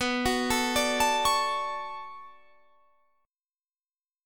Listen to Bm9 strummed